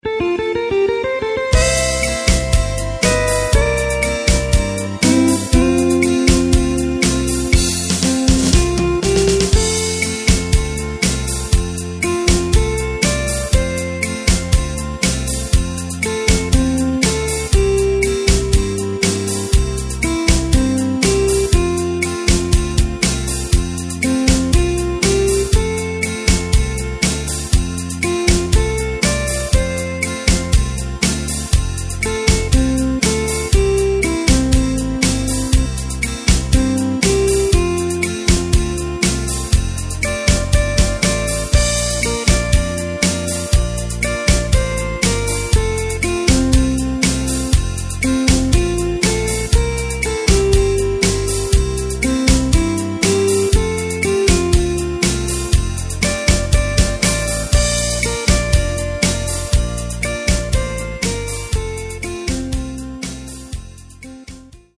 Takt:          4/4
Tempo:         120.00
Tonart:            F
Schlager aus dem Jahr 1985!